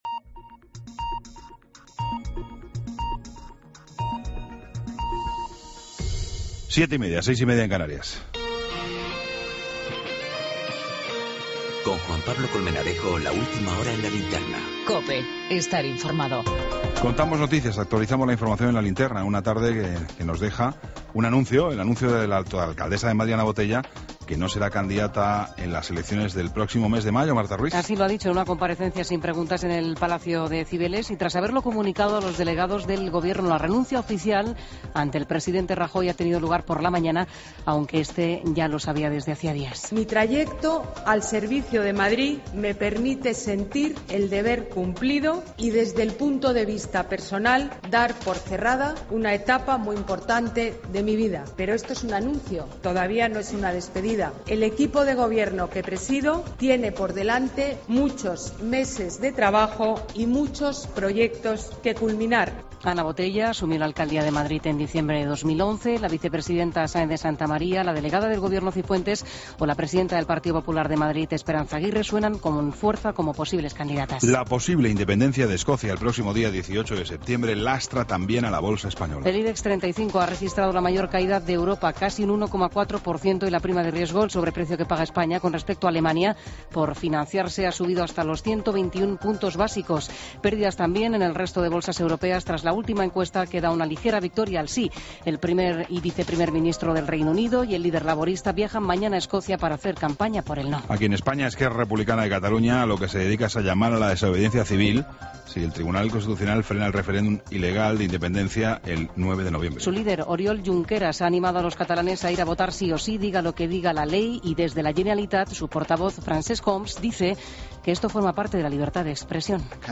Ronda de corresponsales Cope.